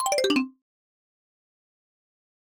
dm_received.ogg